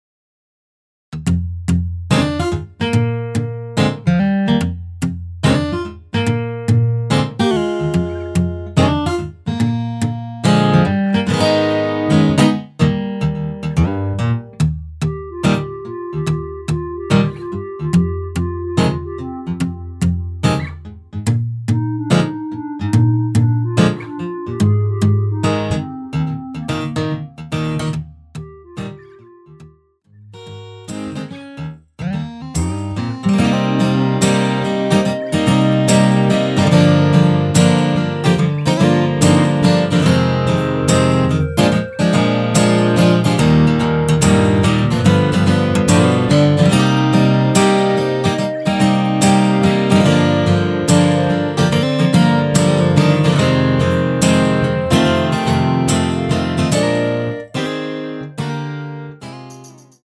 원키에서(-2)내린 멜로디 포함된 MR입니다.
Eb
앞부분30초, 뒷부분30초씩 편집해서 올려 드리고 있습니다.
중간에 음이 끈어지고 다시 나오는 이유는